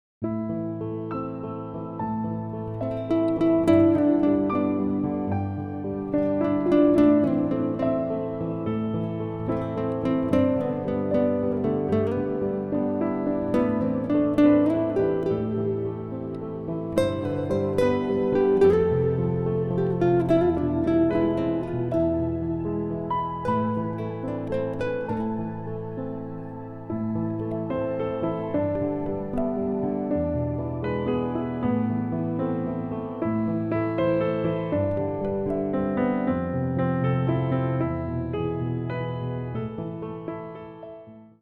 on piano
on guitar
classical guitar